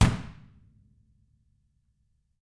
Index of /90_sSampleCDs/ILIO - Double Platinum Drums 1/CD2/Partition A/REMO KICK R